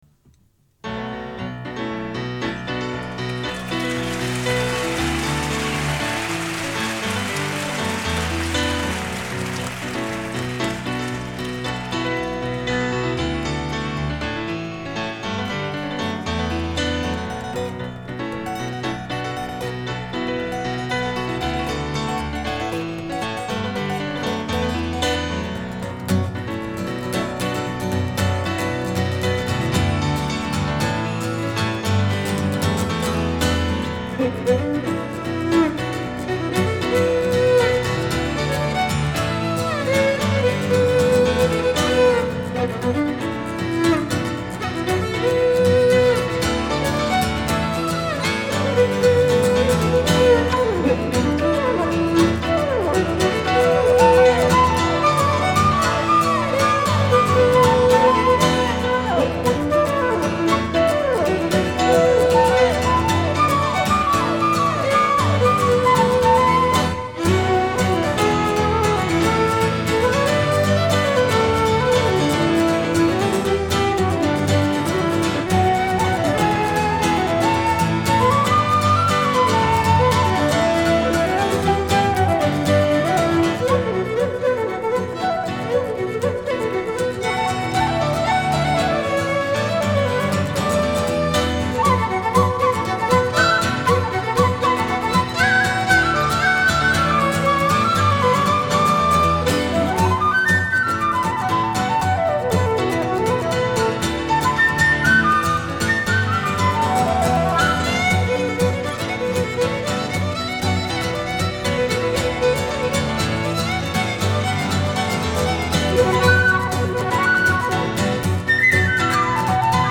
新品/爵士/世界音乐
主奏乐器：人声、民族乐器
难得一闻在西班牙的现场演出版，